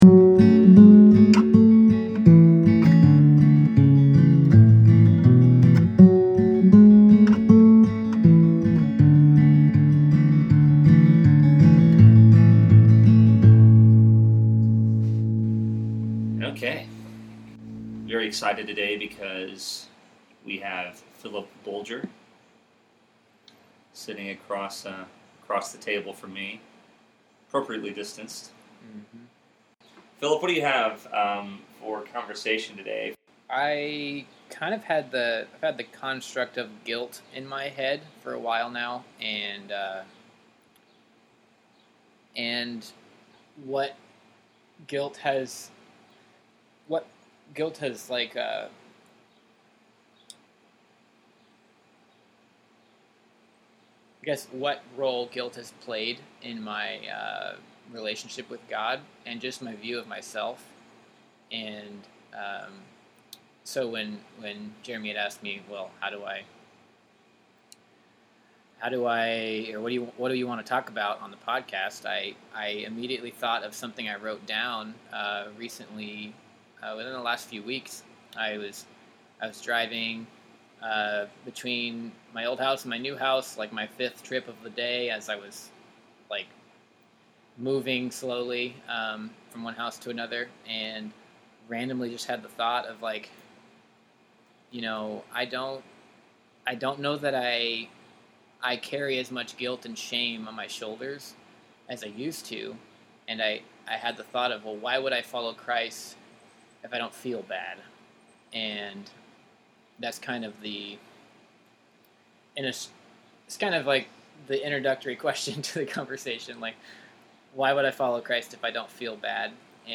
Guilt | A Conversation